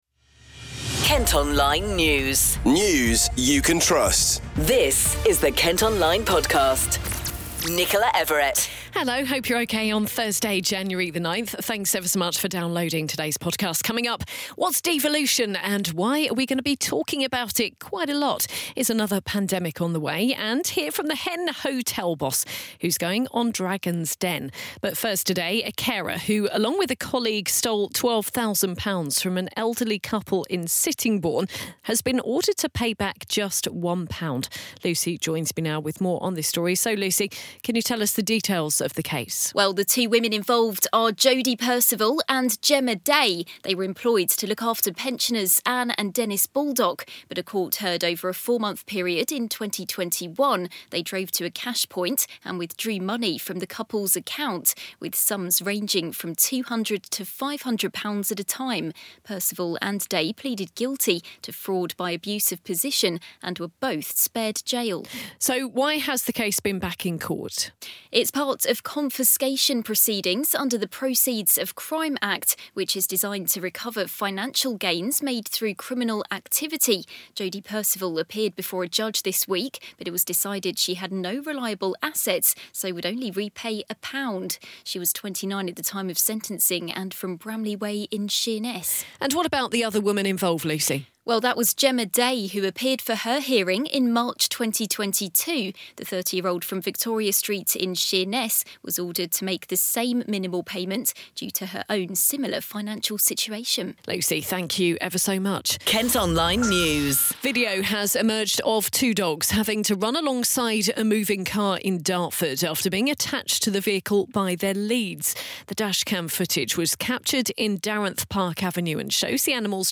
Hear from the leader of Maidstone Borough Council and from a protest outside County Hall.